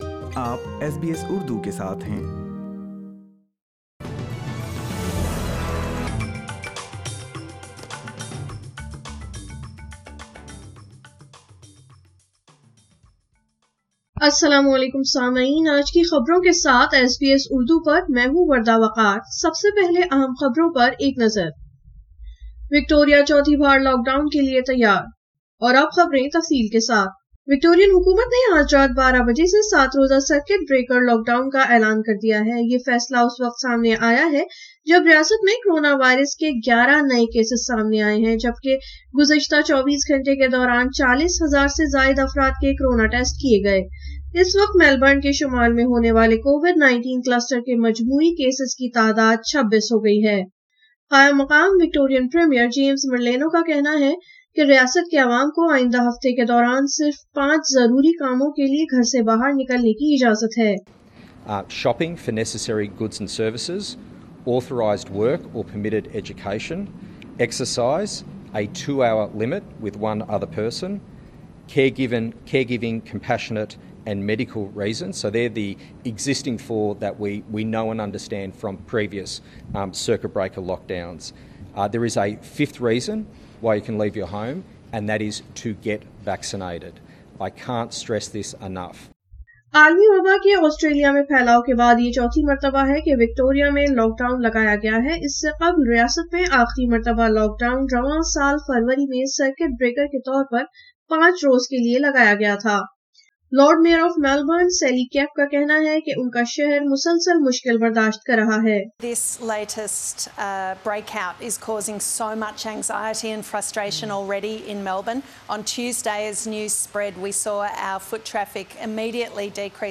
SBS Urdu news 27 May 2021